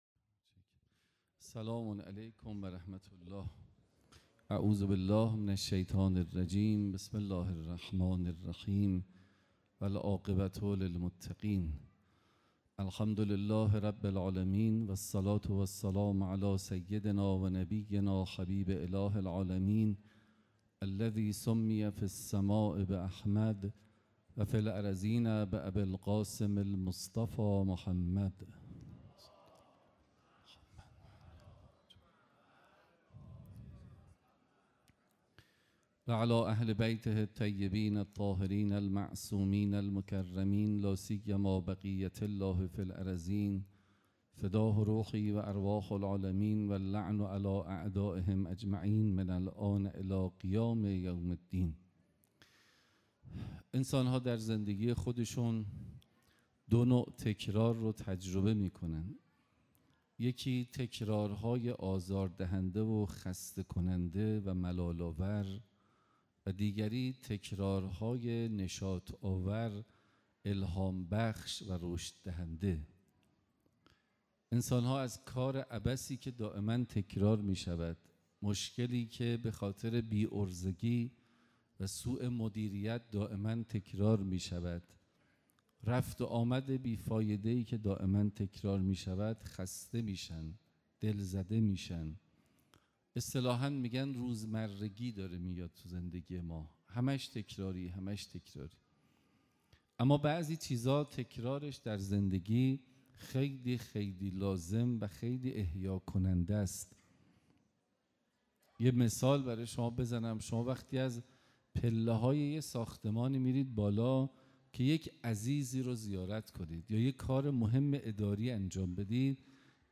سخنرانی
مراسم سالگرد شهید حاج قاسم سلیمانی